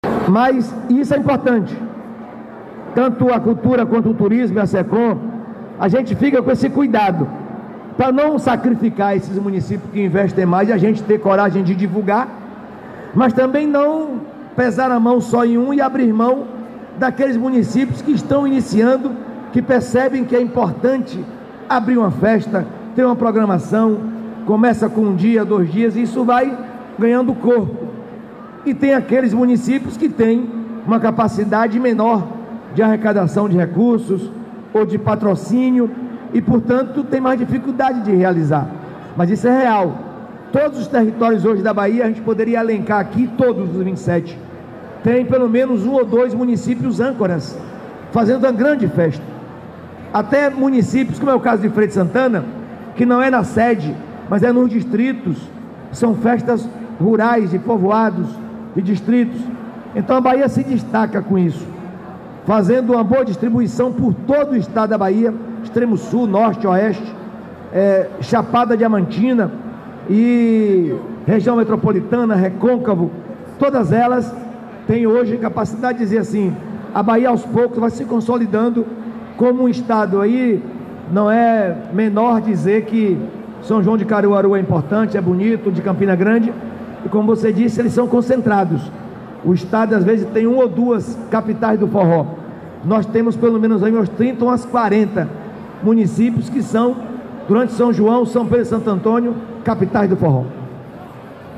🎙Jerônimo Rodrigues, governador da Bahia